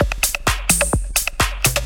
Electrohouse Loop 128 BPM (30).wav